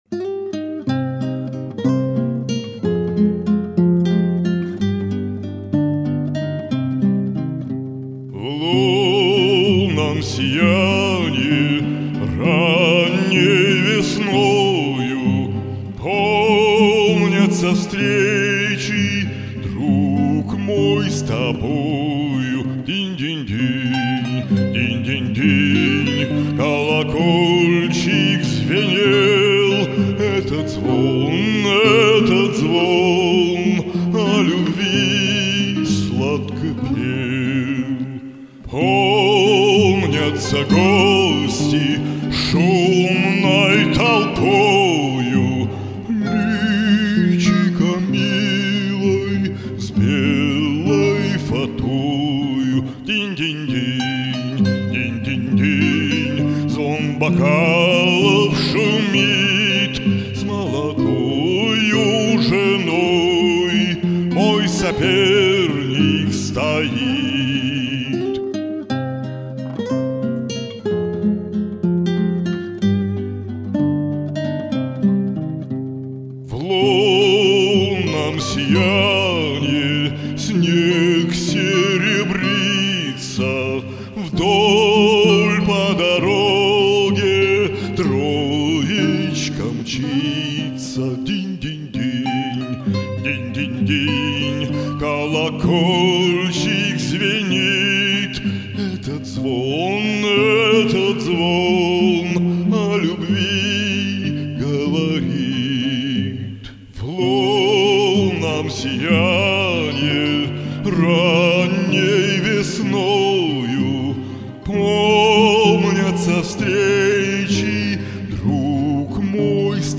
Вокальный ринг.
Так вот , романс вы выбрали прекрасный, легкий , кружевной ... и теноровый.